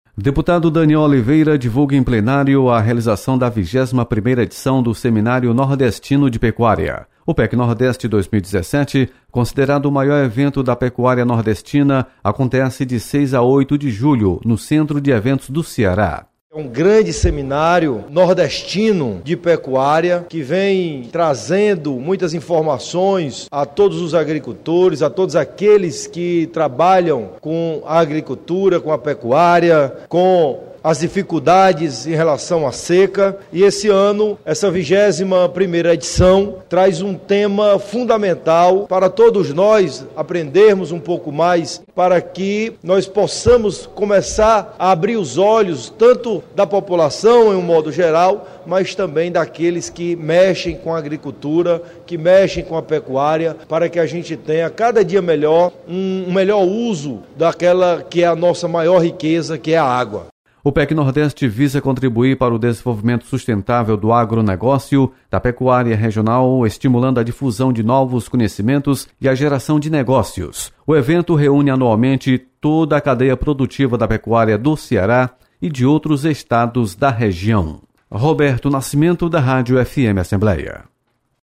Deputado Danniel Oliveira destaca contribuição do PecNordeste para o setor agropecuário. Repórter